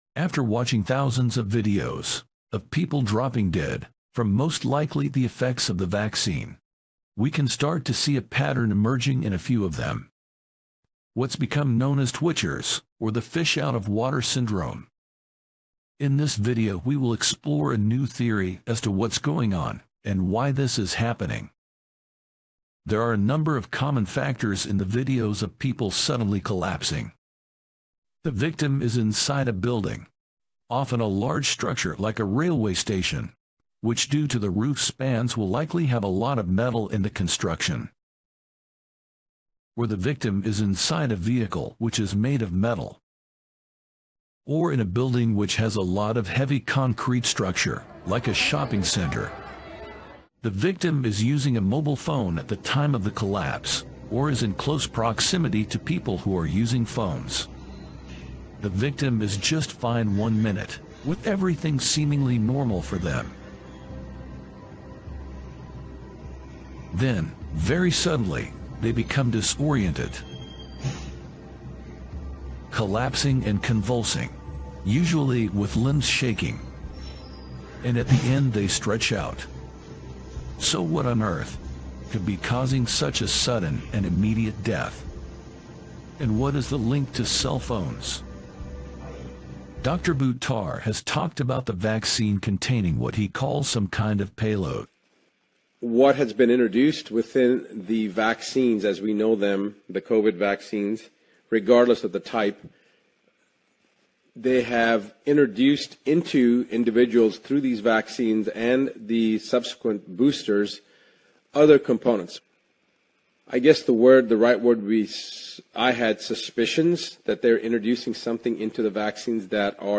Music at the end